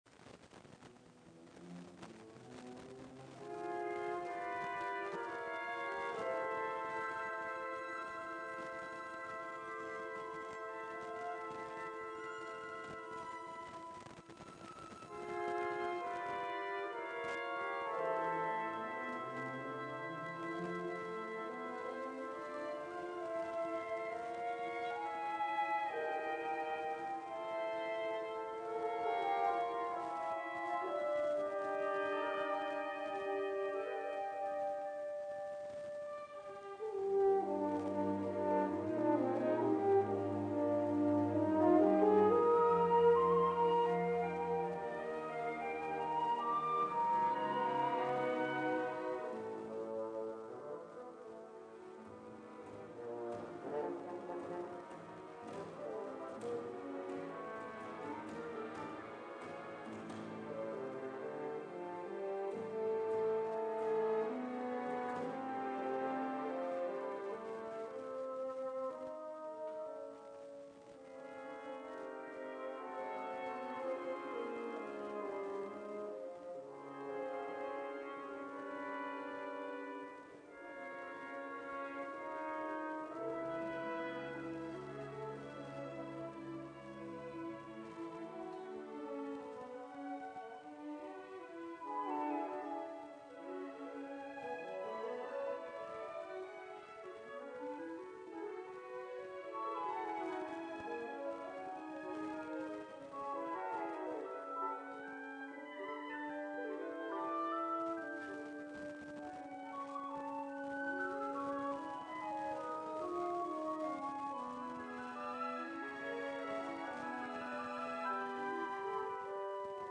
Musica Classica / Sinfonica